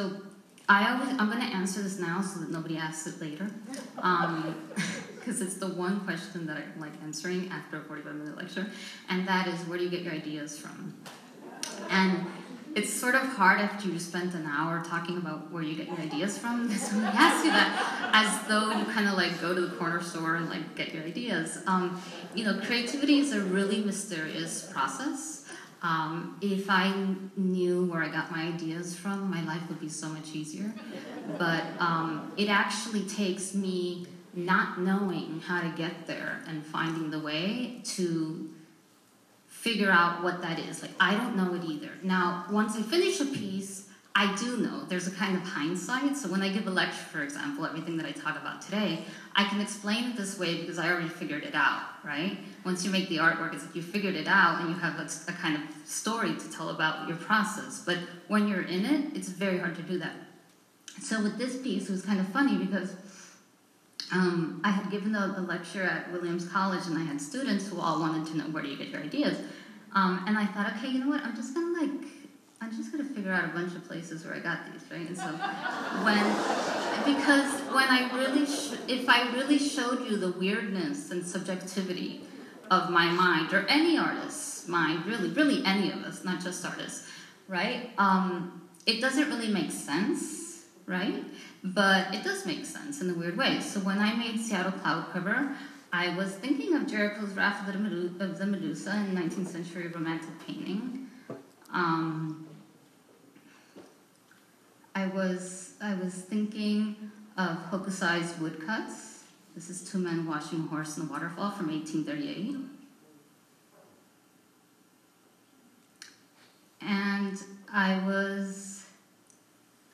For those of you who were unable to attend the sold-out lecture by internationally known installation artist Teresita Fernández last Wednesday evening (May 18, 2016) at the Phoenix Art Museum, I thought I would share a short audio excerpt. You’ll hear Fernández candidly talking about her relationship with creativity, which I think will strike a chord with many artists. The artwork she refers to is “Seattle Cloud Cover,” a glass bridge bearing saturated color photographs that spans Seattle’s Olympic Sculpture Park.